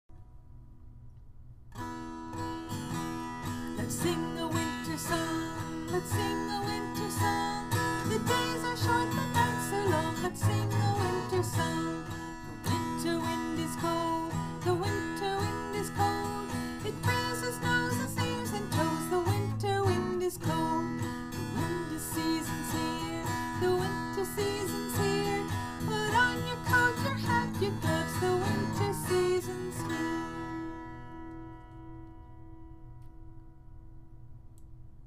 Tune: "The Farmer in the Dell"